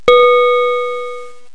1 channel
bellnot2.mp3